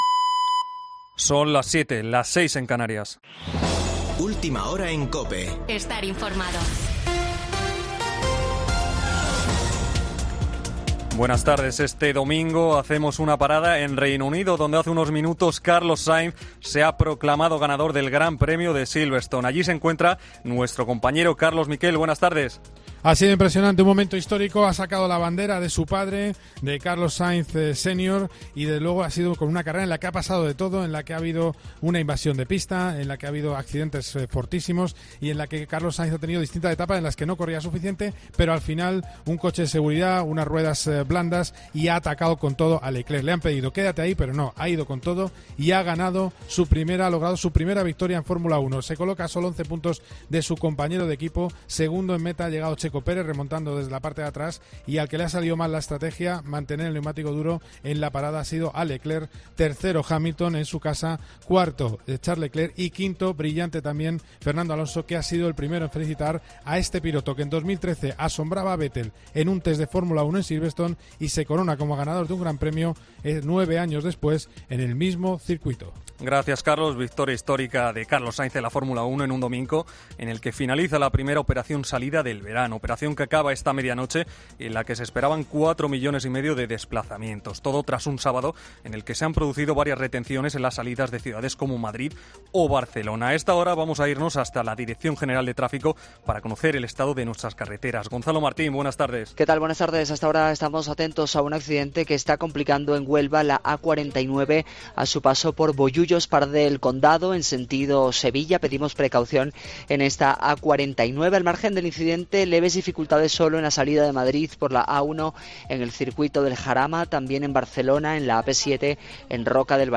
Boletín de noticias de COPE del 3 de julio de 2022 a las 19.00 horas